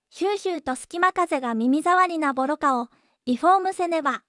voicevox-voice-corpus / ROHAN-corpus /WhiteCUL_たのしい /ROHAN4600_0008.wav